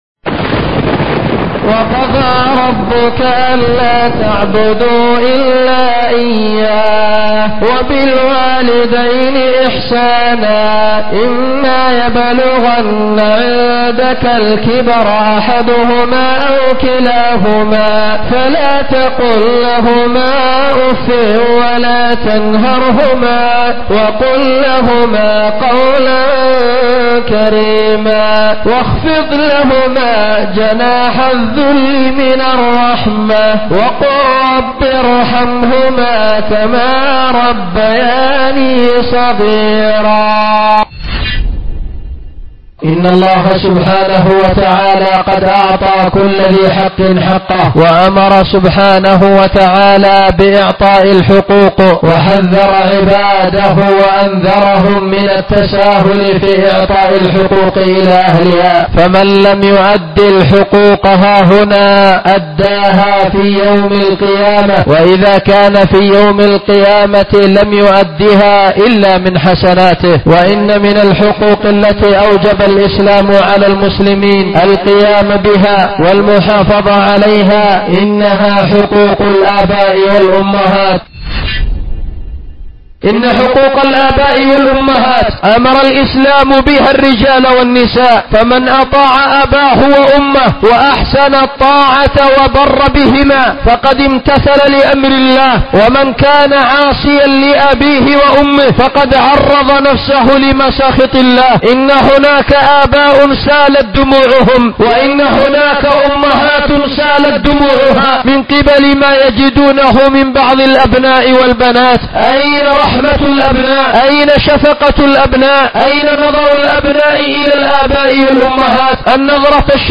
من أعظم الحقوق والواجبات بر الآباء الأمهات ـ خطبة بجامع الخير بصنعاء
أُلقيت بمسجد الخير ـ اليمن ـ صنعاء